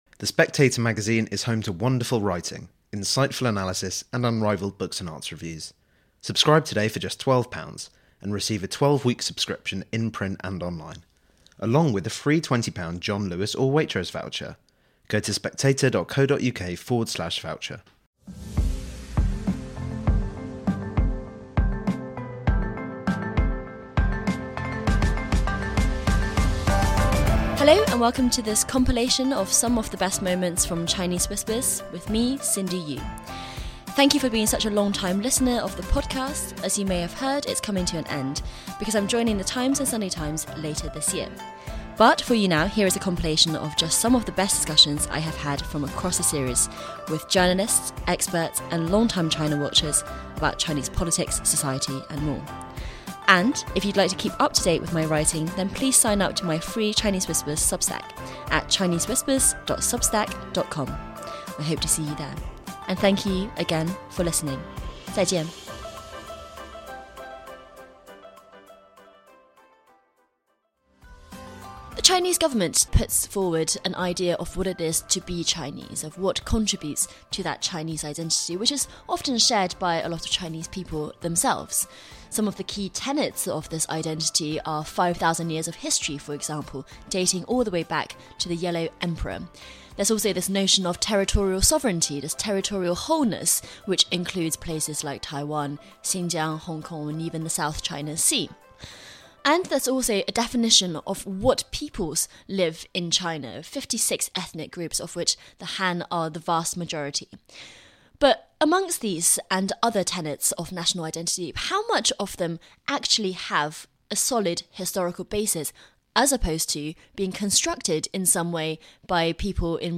As Chinese Whispers comes to an end, here is a compilation of some of the best discussions